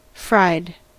Ääntäminen
Ääntäminen US Haettu sana löytyi näillä lähdekielillä: englanti Fried on sanan fry partisiipin perfekti.